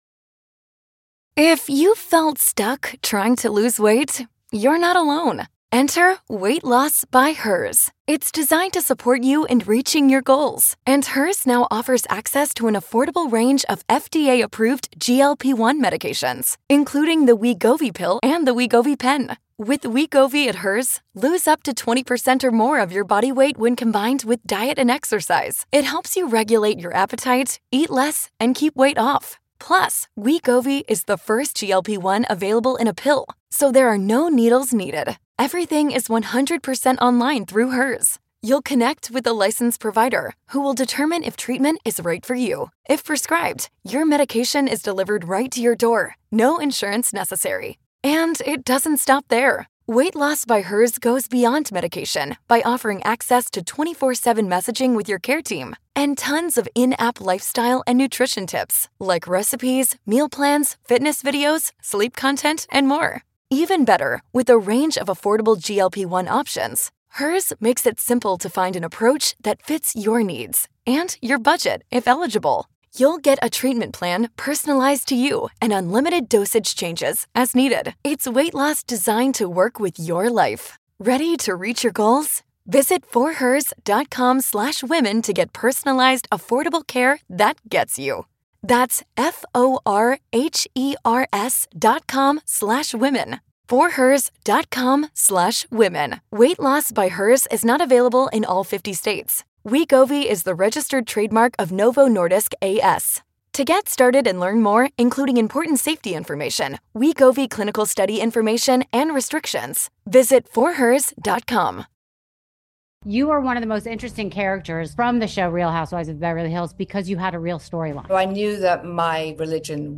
Join us this Halloween for an exclusive conversation with Real Housewives of Beverly Hills alum and practicing Wiccan, Carlton Gebbia. Known for her gothic style and spiritual beliefs, Carlton opens up about what’s misunderstood about witchcraft, the practices closest to her heart, and dishes on her true feelings about Kyle Richards.